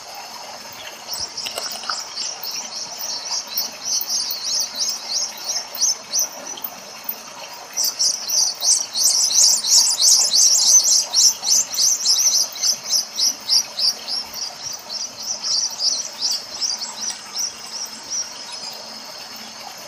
White-collared Swift